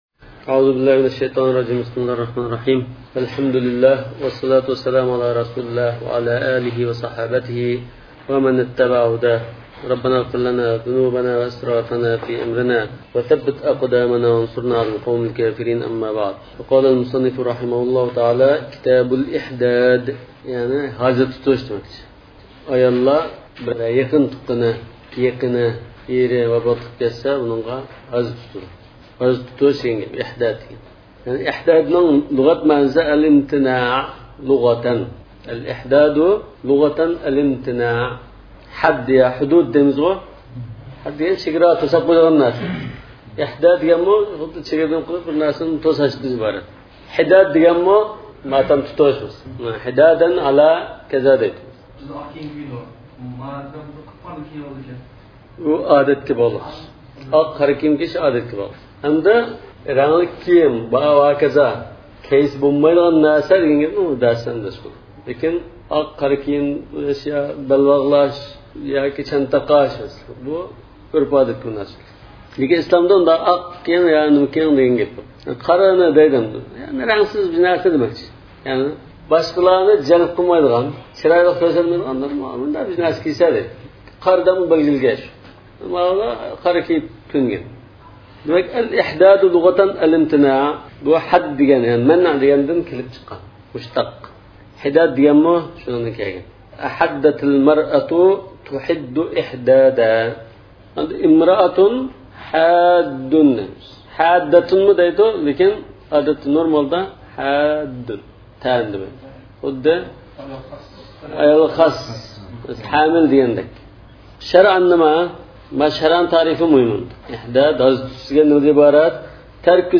ئاۋازلىق دەرسلەر